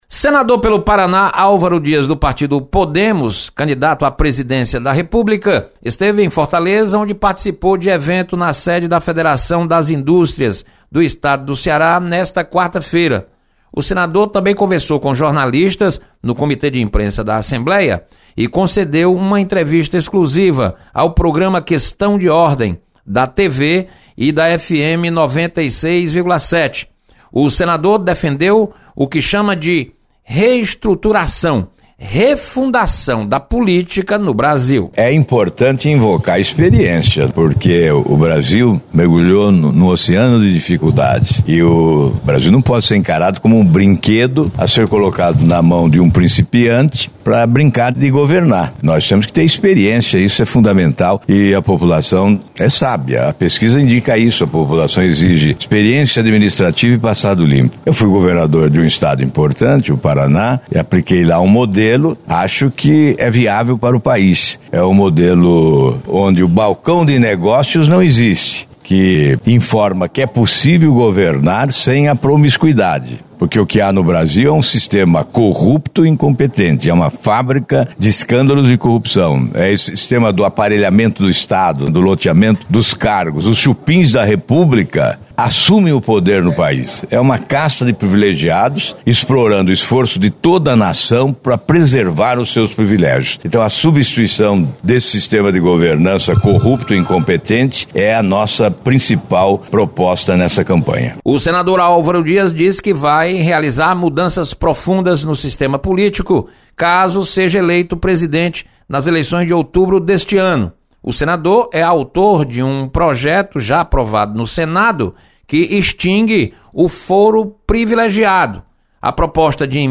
Senador Álvaro Dias é convidado do programa Questão de Ordem.